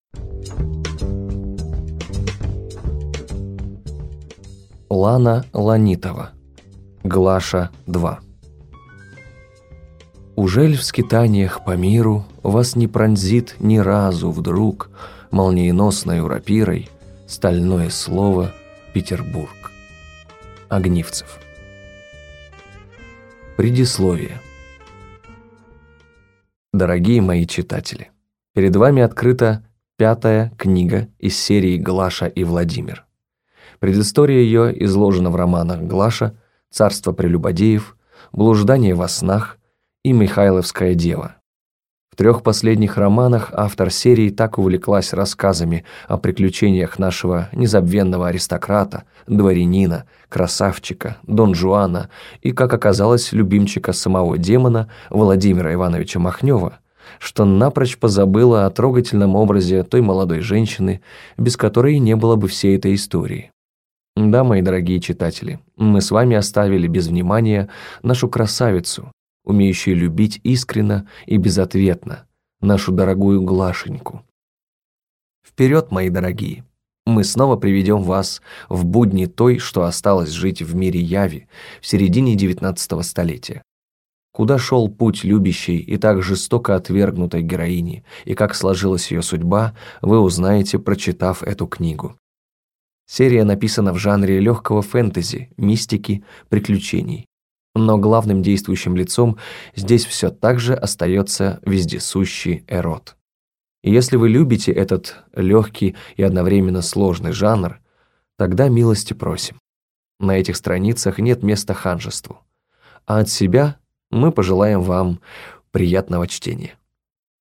Аудиокнига Глаша 2 | Библиотека аудиокниг